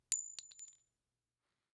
CassingDrop 01.wav